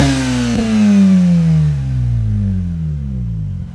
rr3-assets/files/.depot/audio/Vehicles/wr_02/wr_02_decel.wav